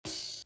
Neighborhood Watch Open Hat.wav